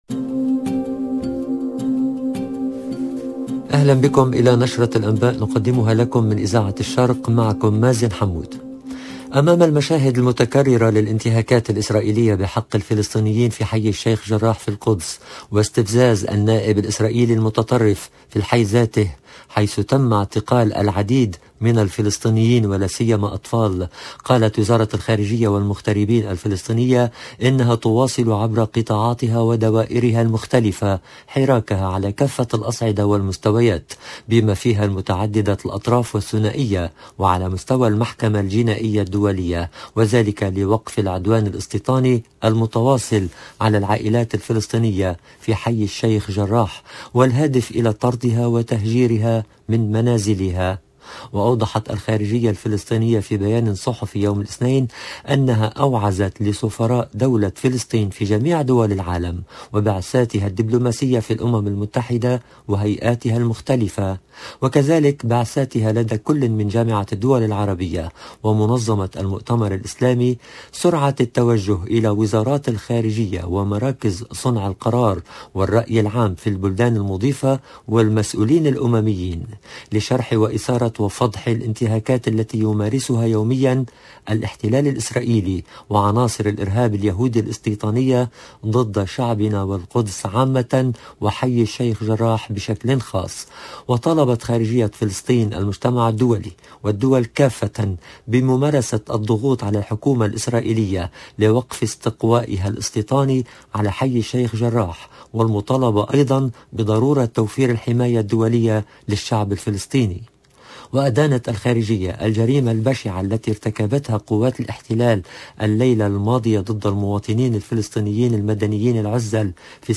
LE JOURNAL DU SOIR EN LANGUE ARABE DU 15/02/22